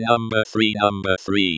Audio test: Localizzazione spaziale del suono
08-number-three.wav